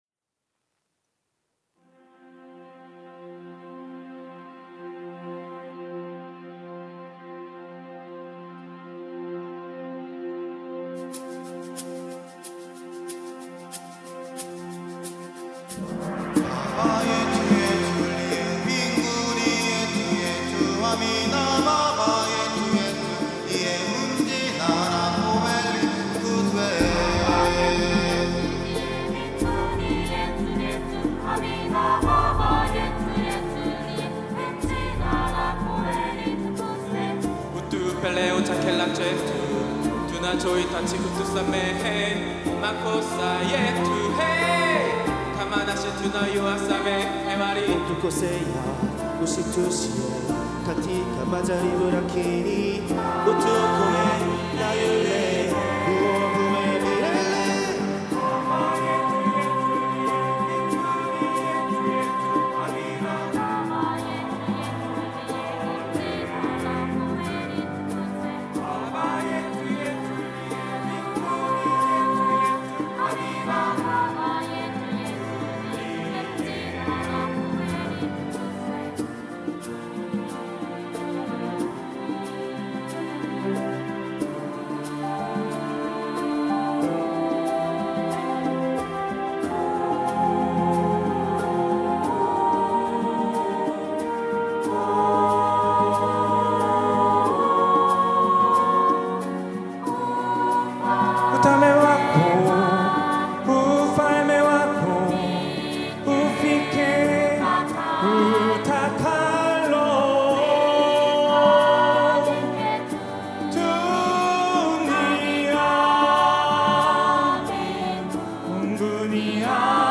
특송과 특주 - 바바 예투 : 우리 아버지